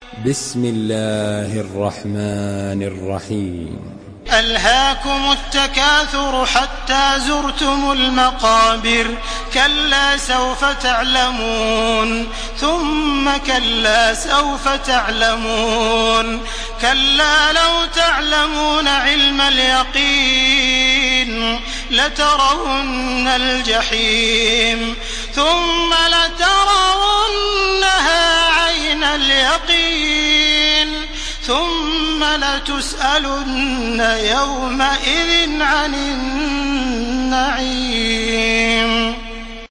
Surah আত-তাকাসুর MP3 in the Voice of Makkah Taraweeh 1431 in Hafs Narration
Murattal